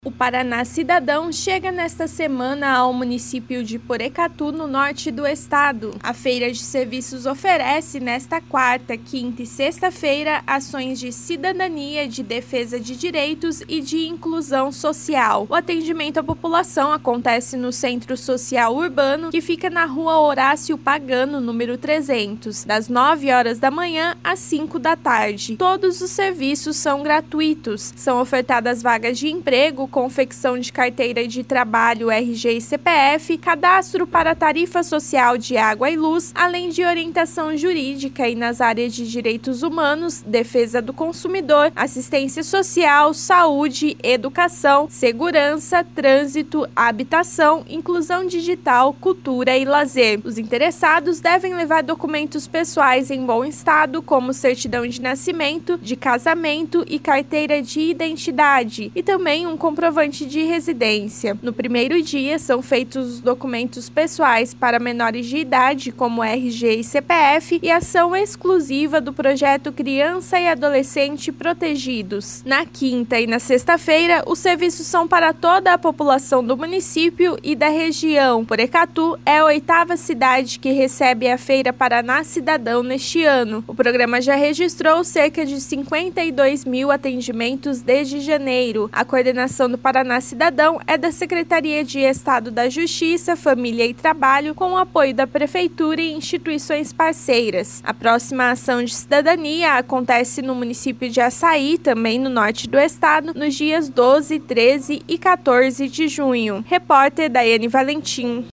Saiba os detalhes na matéria da repórter
Outras notícias na programação da Rádio Cultura AM 930